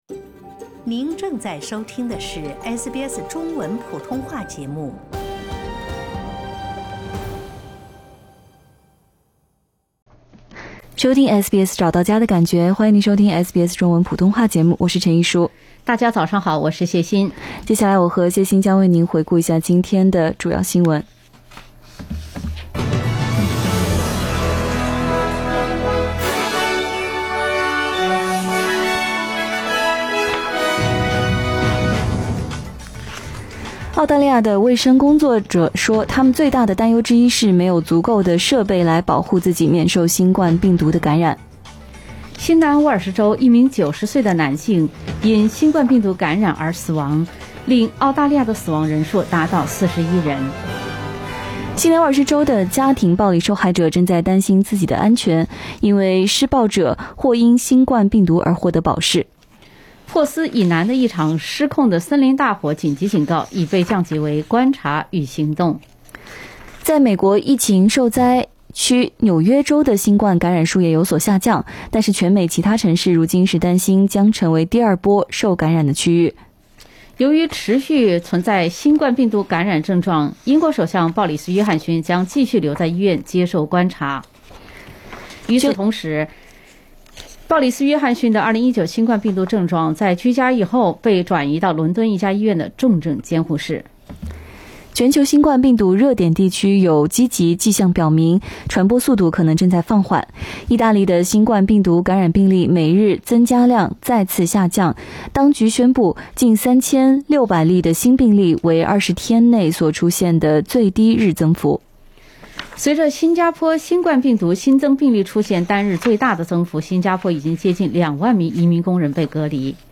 SBS早新闻（4月7日）
SBS Chinese Morning News Source: Shutterstock